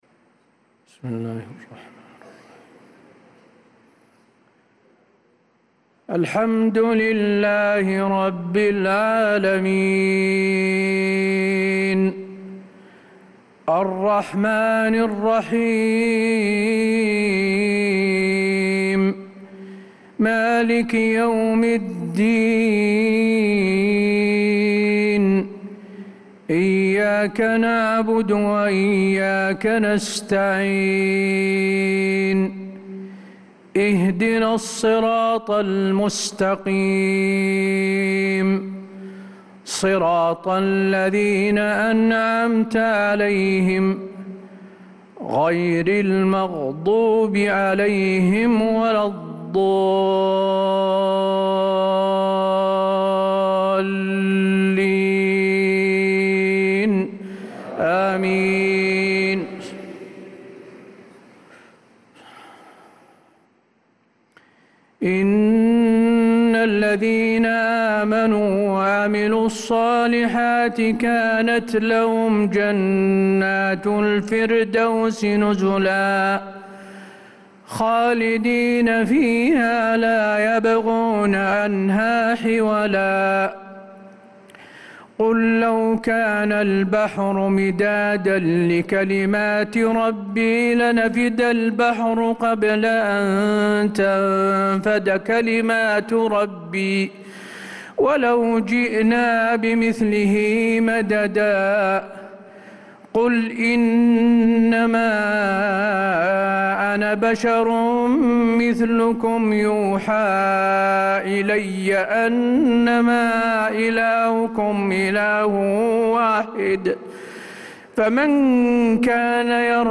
صلاة المغرب للقارئ حسين آل الشيخ 21 محرم 1446 هـ
تِلَاوَات الْحَرَمَيْن .